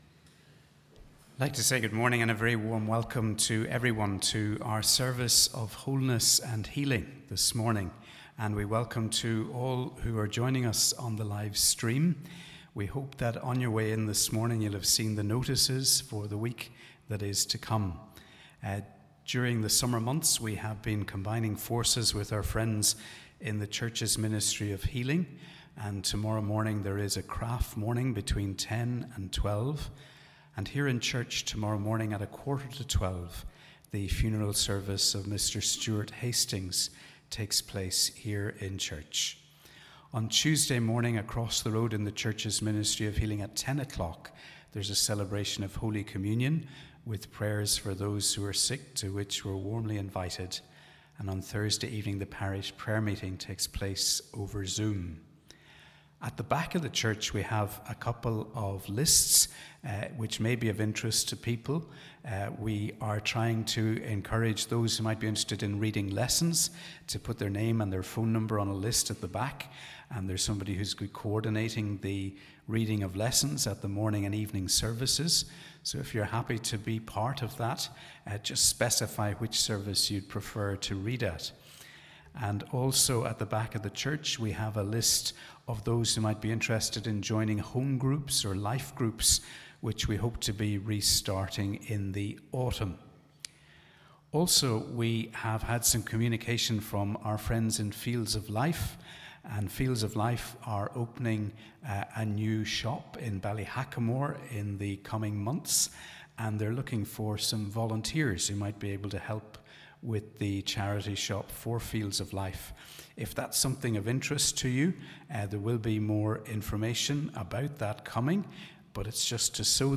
We welcome you to our service of Wholeness & Healing.